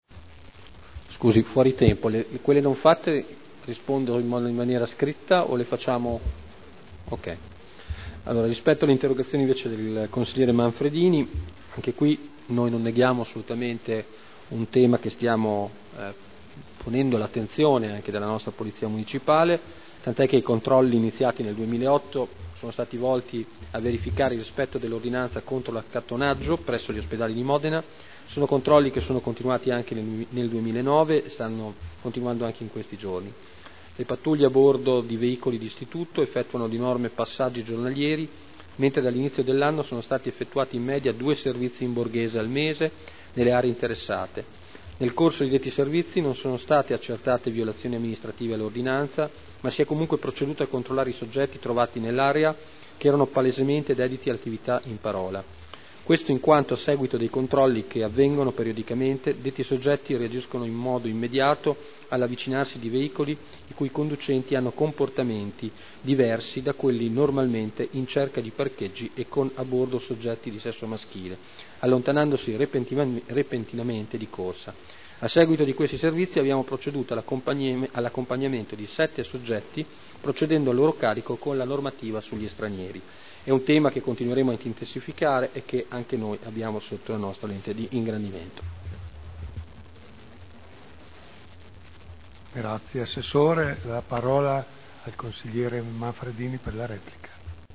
Antonino Marino — Sito Audio Consiglio Comunale